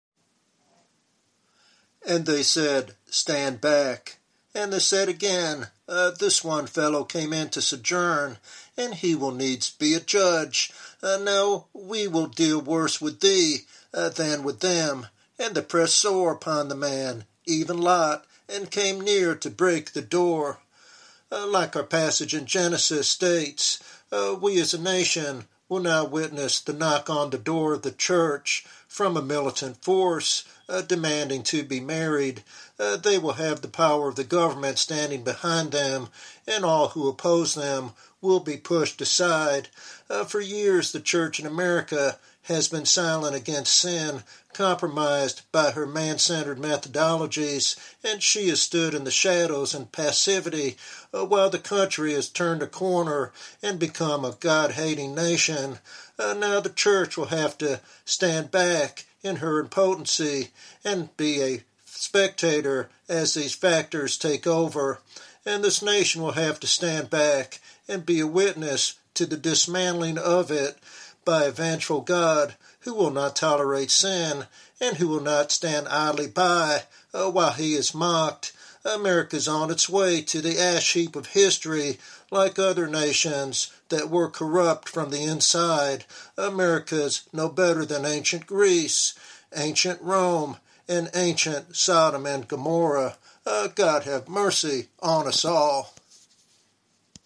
Sermon Outline
While the tone is largely warning and prophetic, there is a call for mercy and repentance implying hope if the nation turns back to God.